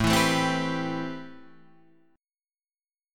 A Minor 9th